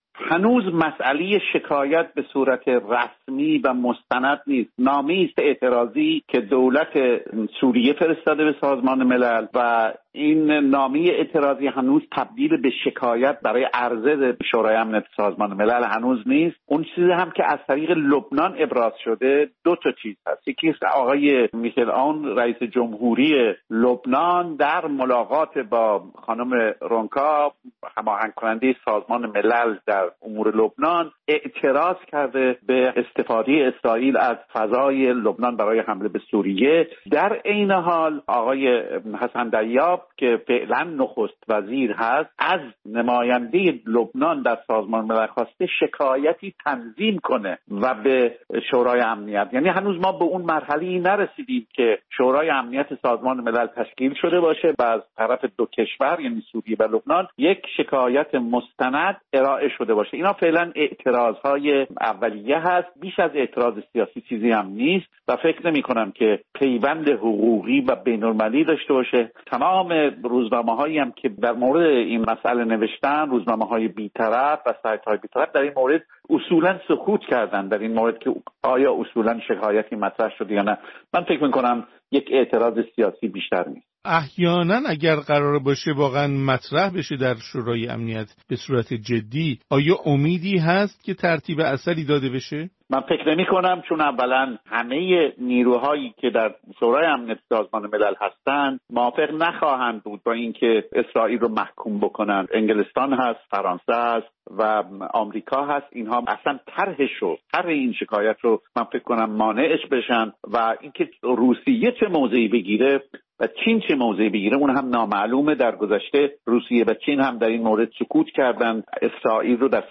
گفت‌وگوی
تحلیلگر مسائل خاورمیانه